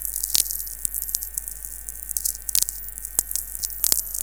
запись онч сигнала